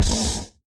mob / horse / skeleton / hit4.ogg